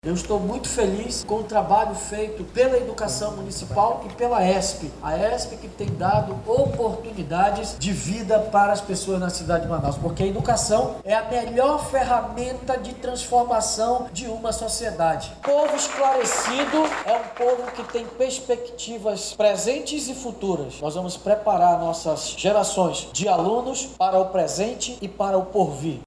Durante o evento, o prefeito de Manaus, Davi Almeida, caracterizou os projetos como oportunidades de educação.